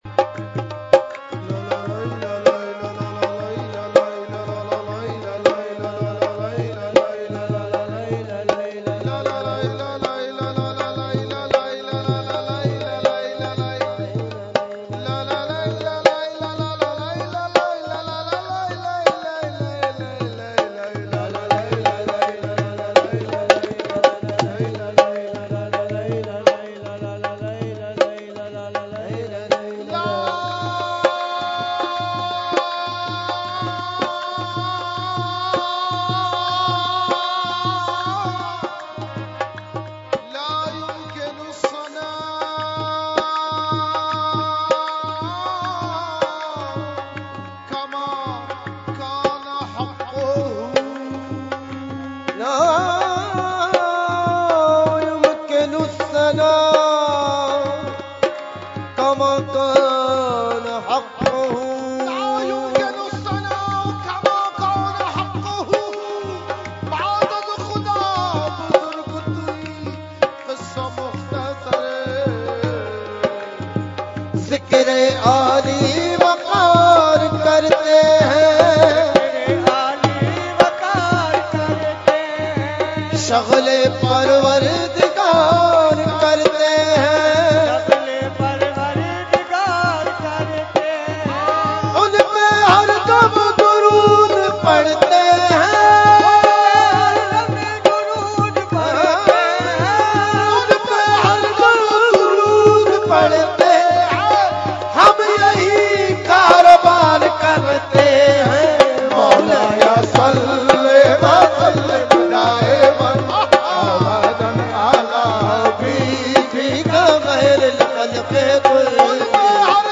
Category : Qasida Burda Shareef | Language : UrduEvent : Urs Qutbe Rabbani 2019